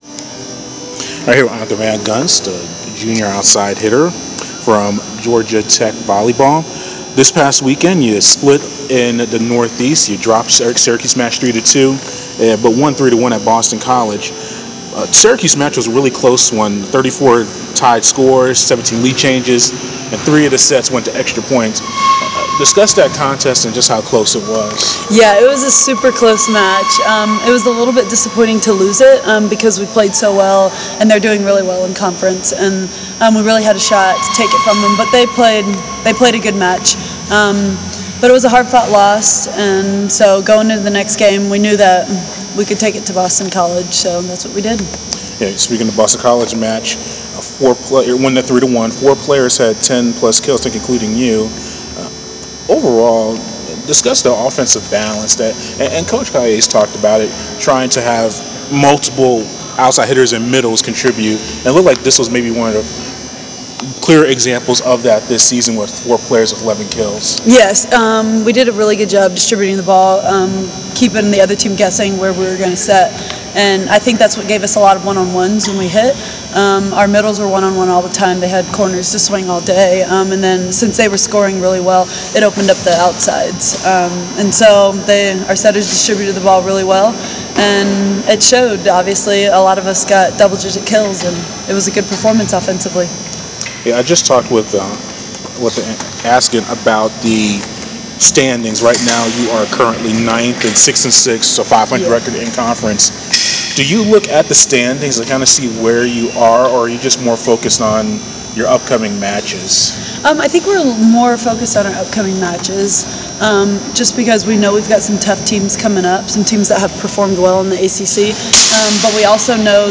Fighting Peaches: Interview